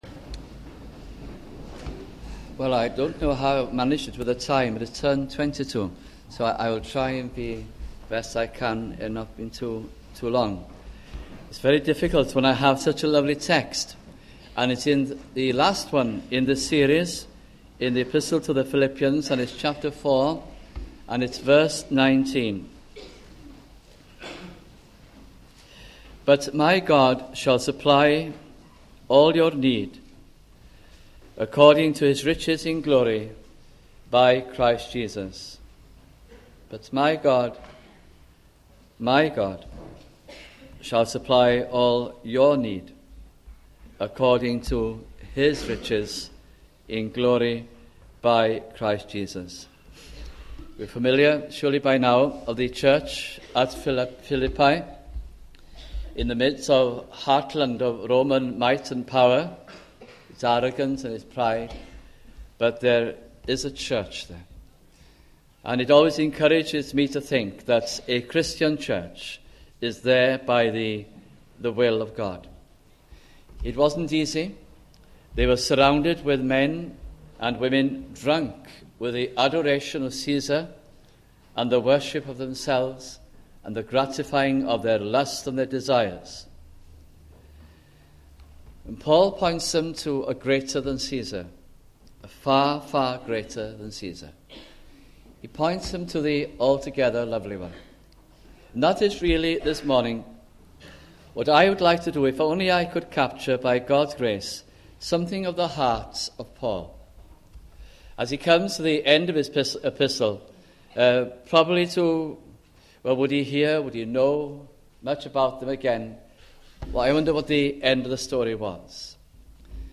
» Philippians Series 1989-90 » sunday morning messages